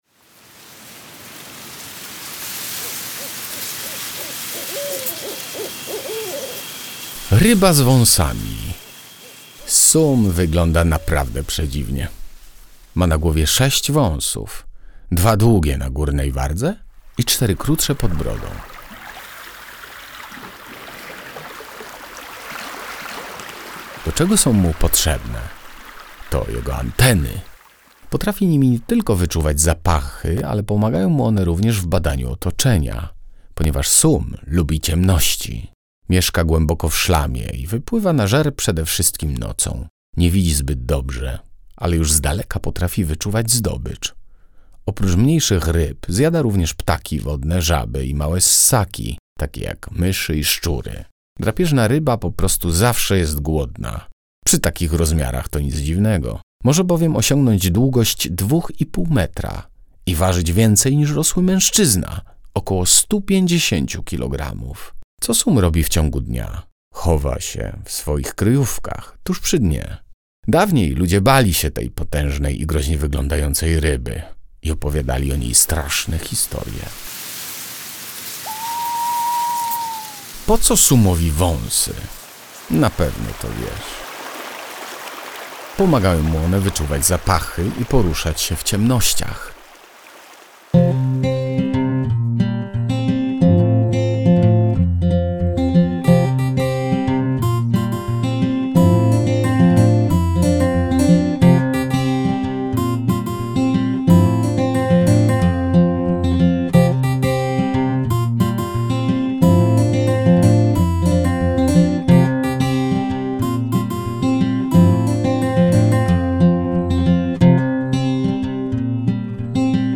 Na dołączonej do książki płycie CD i pod kodem QR znajduje się interesująco opowiedziana historia, której towarzyszą odgłosy przyrody.